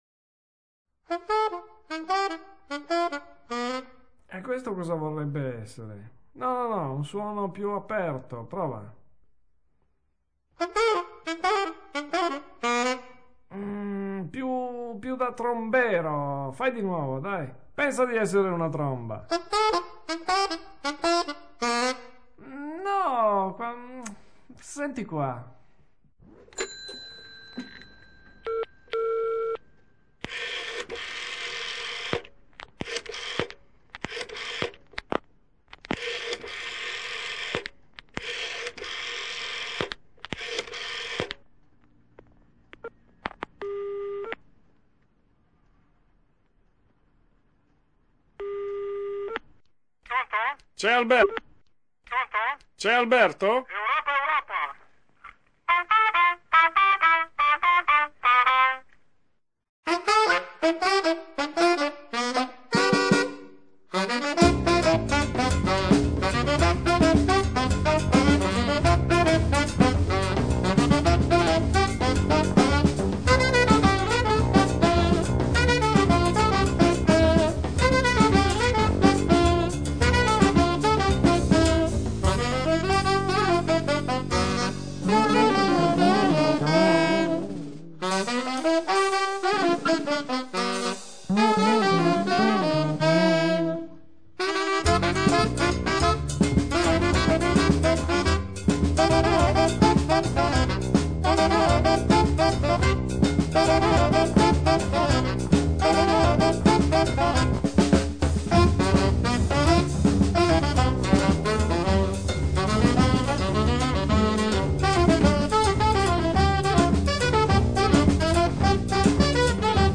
Recorded at Blue Record Studios on October 27,28,29 1989